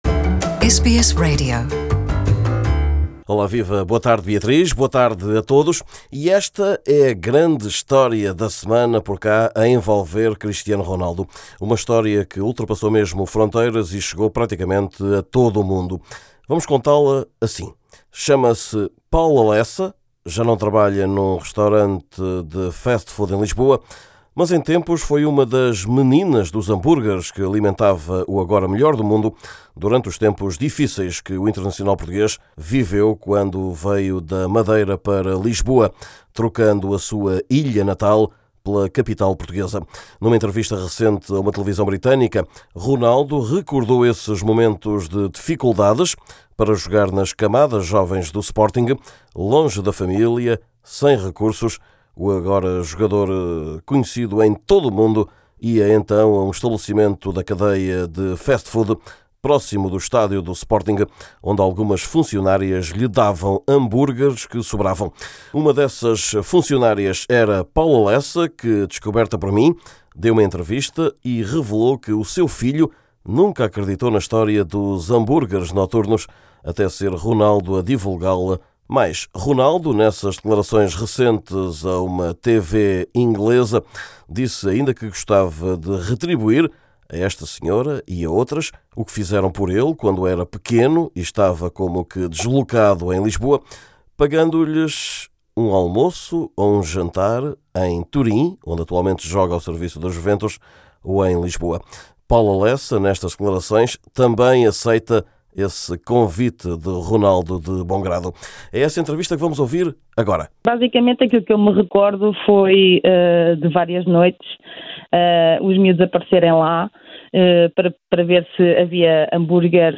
É essa entrevista que aqui escutamos. Neste boletim, lugar ainda para o balanço da semana europeia de clubes, com cinco equipas lusas envolvidas.